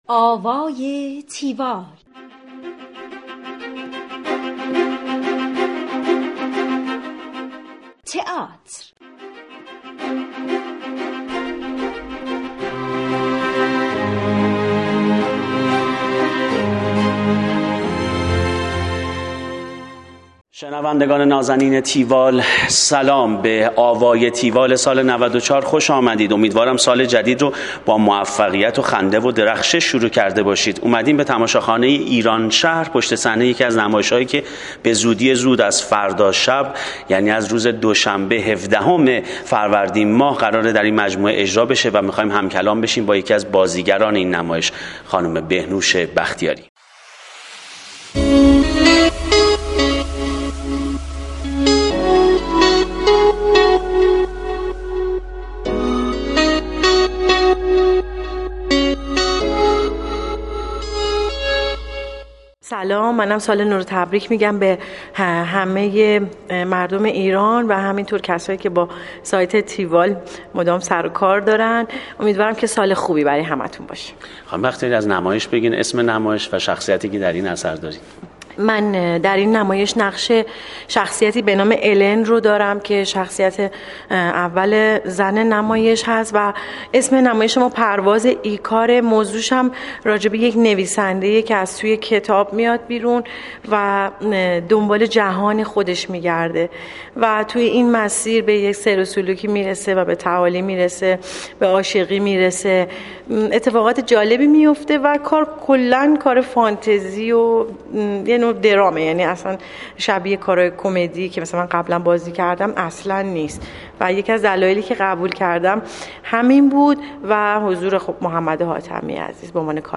گفتگوی تیوال با بهنوش بختیاری
tiwall-interview-behnoshbakhtiari.mp3